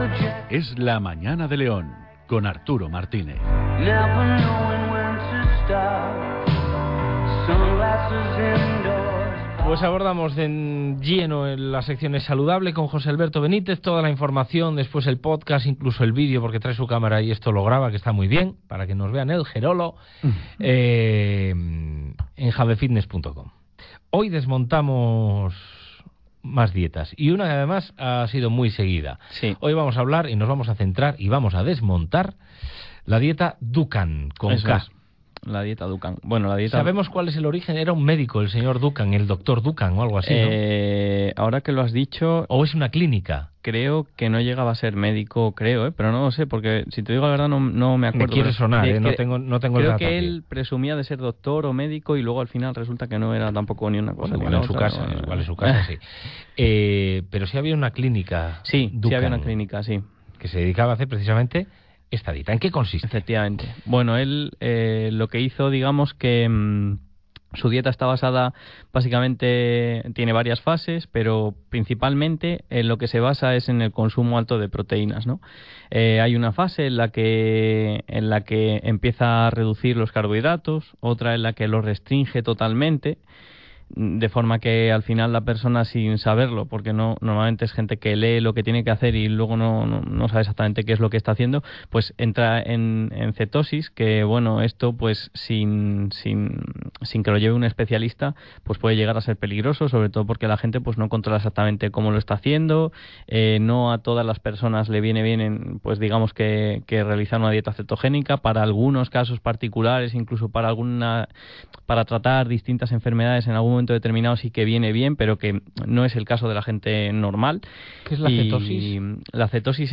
Hoy os traigo el cuadragésimo quinto programa de la sección que comenzamos en la radio local hace unos meses y que hemos denominado Es Saludable.